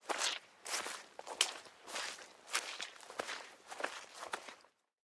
Звуки грязи
Шаги по мокрой грязи вариант 2